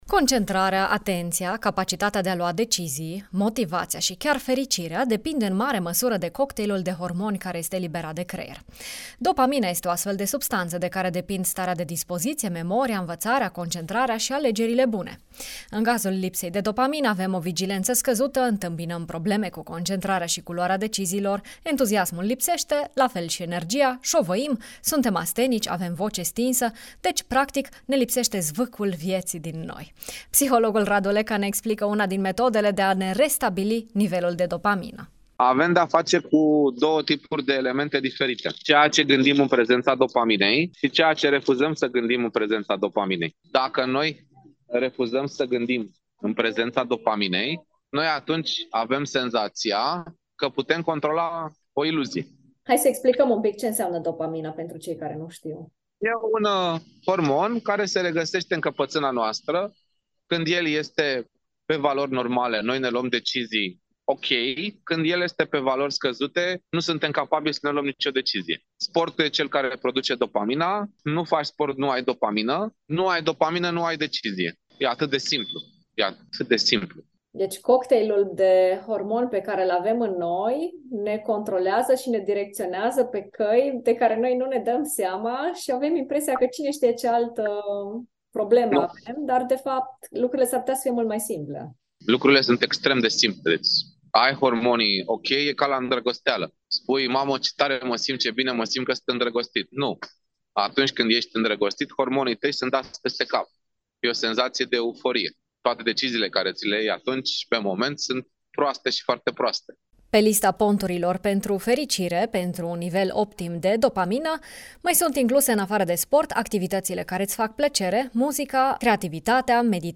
psiholog, ne explică una din metodele de a ne restabili nivelul de dopamină: